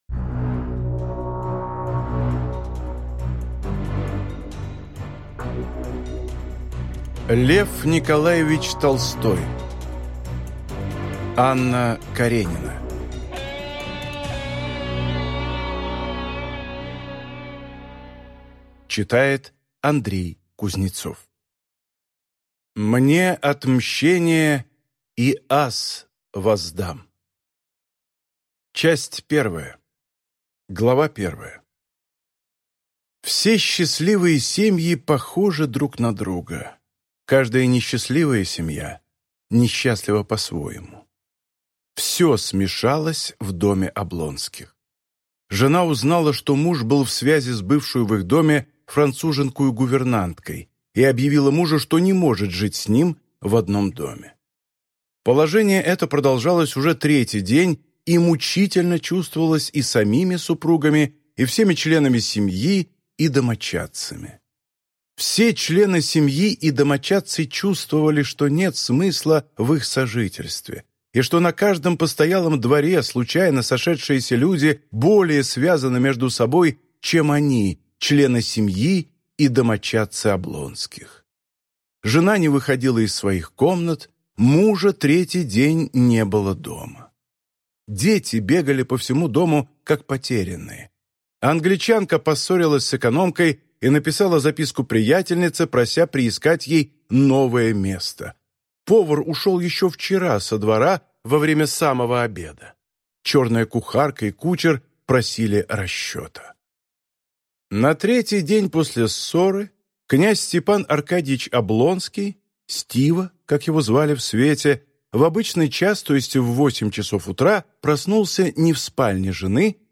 Аудиокнига Анна Каренина (Книга 1) | Библиотека аудиокниг